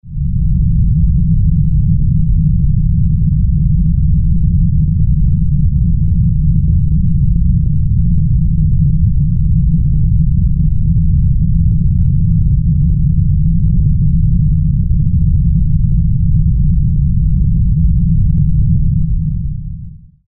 На этой странице собраны звуки землетрясений разной интенсивности: от глухих подземных толчков до разрушительных катаклизмов.
Звук подлинного землетрясения при движении тектонических плит